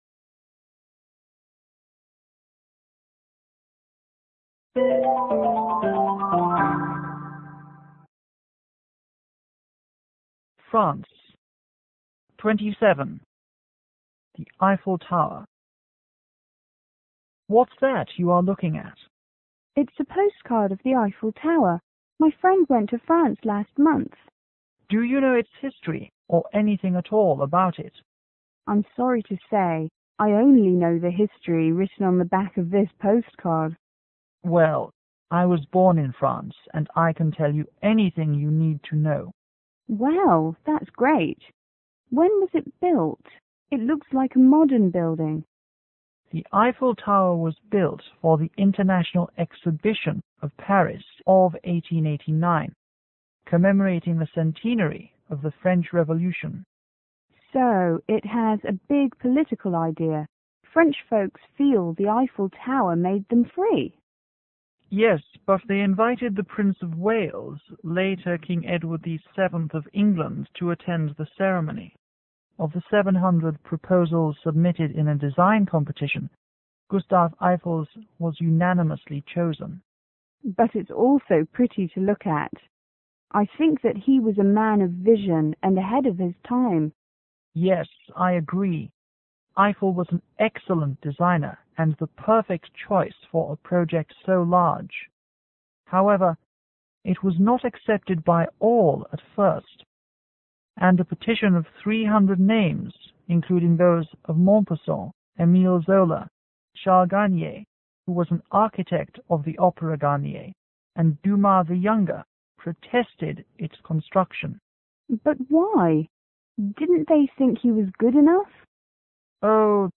S1 : Student l       S2 : Student 2